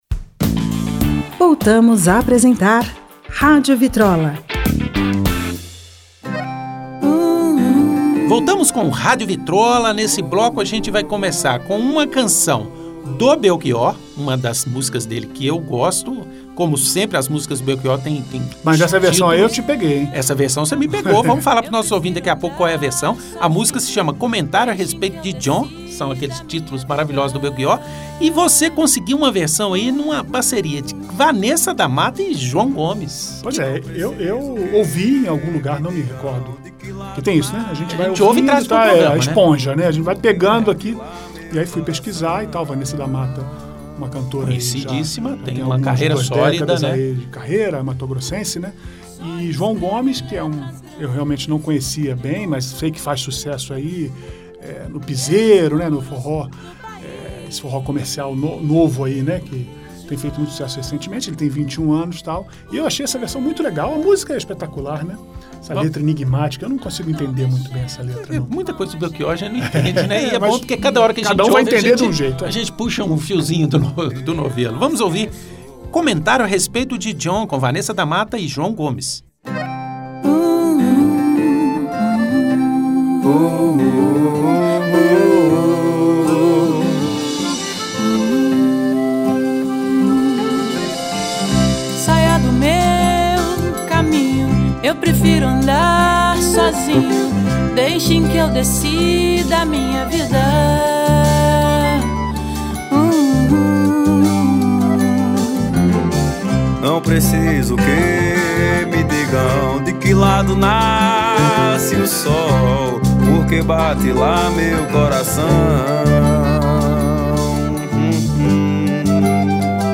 música popular brasileira